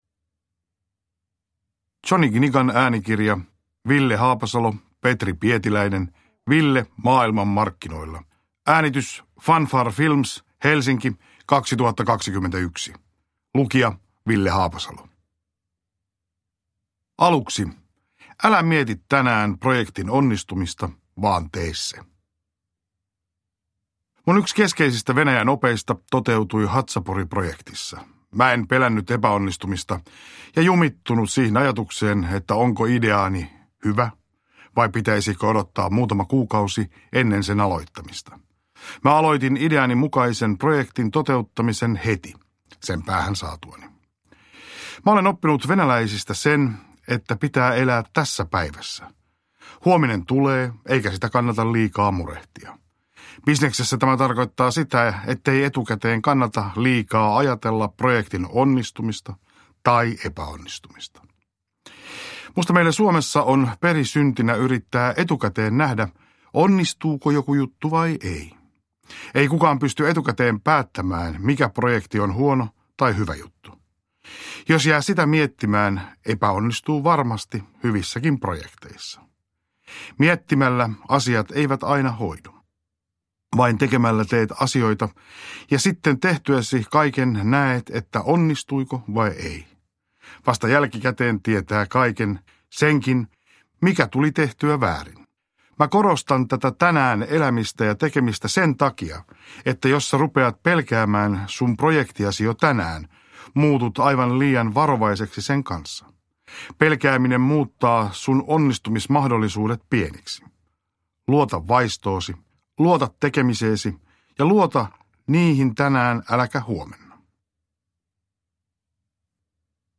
Ville maailman markkinoilla – Ljudbok
Uppläsare: Ville Haapasalo